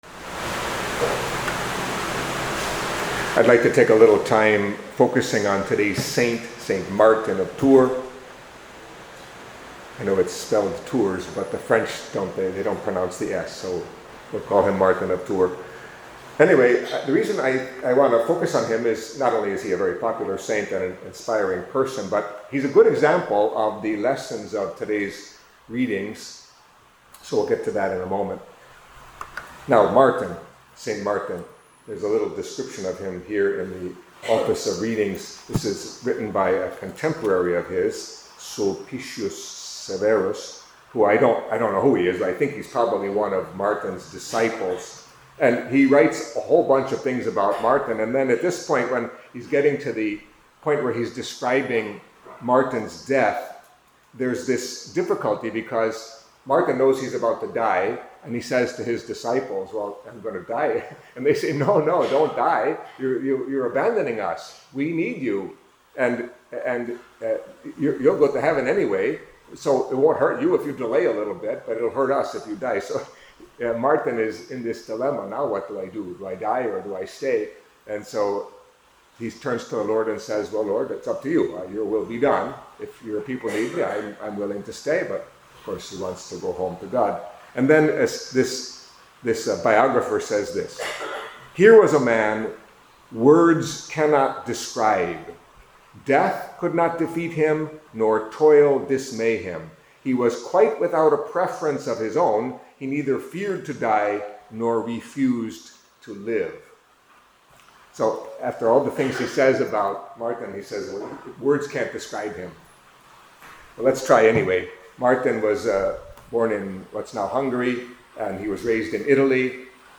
Catholic Mass homily for Saturday of the Thirty-First Week in Ordinary Time